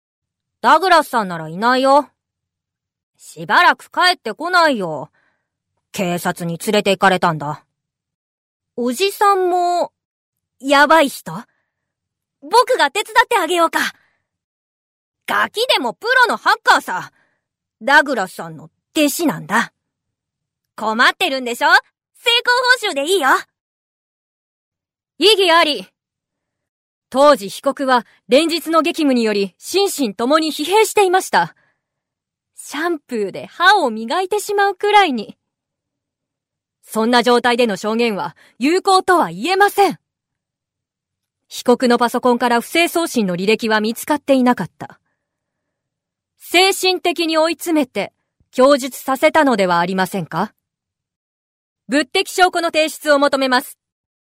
Voice Sample
セリフ